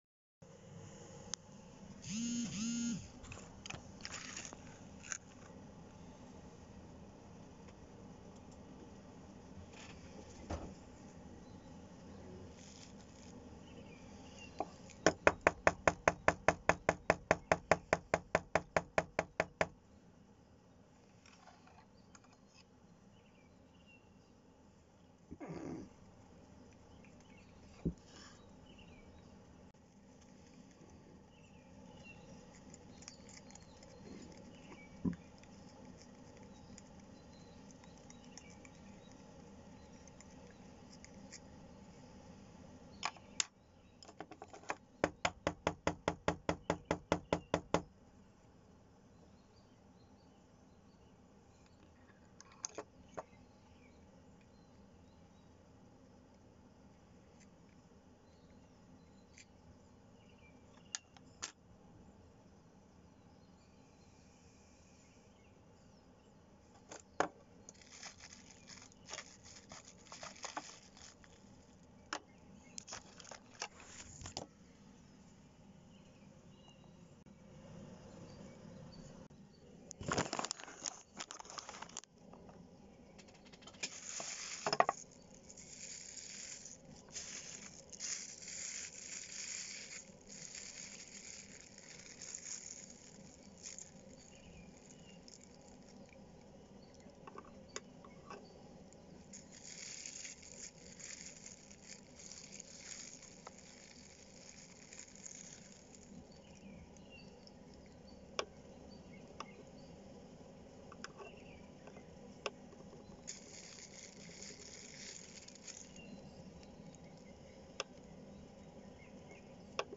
だらしのないクリエイターが大自然の中でパイプでシャグタバコを肺喫煙する音。